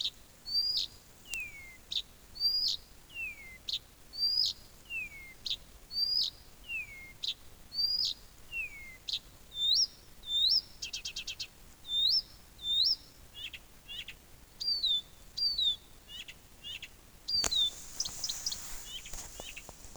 Hvilken fuglesang?
Mistenker myrsanger.